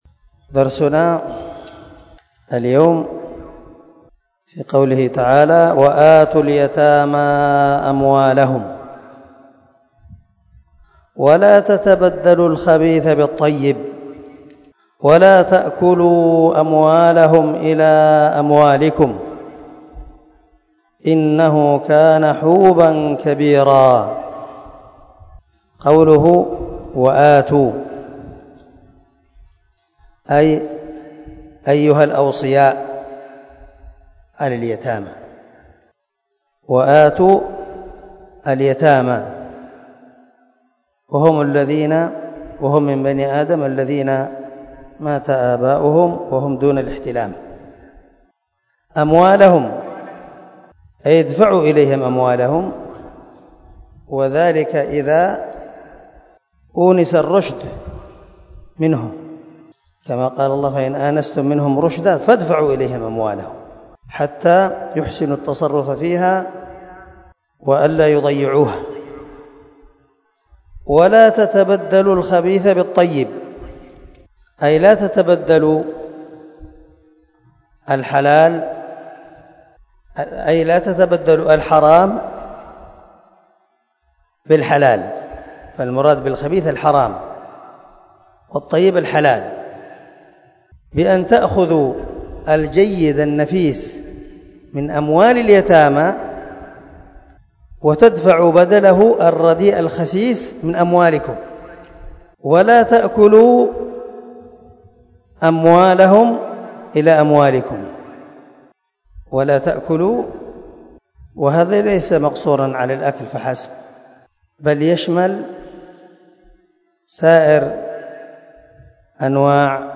234الدرس 2 تفسير آية ( 2 ) من سورة النساء من تفسير القران الكريم مع قراءة لتفسير السعدي